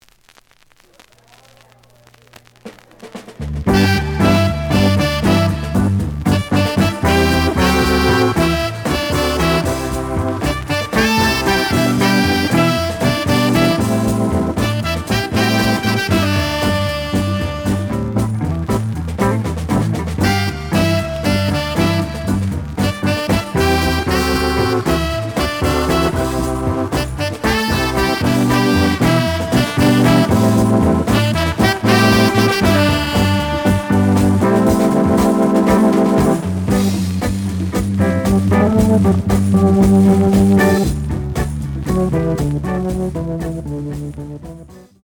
The audio sample is recorded from the actual item.
●Genre: Jazz Funk / Soul Jazz
Slight noise on A side.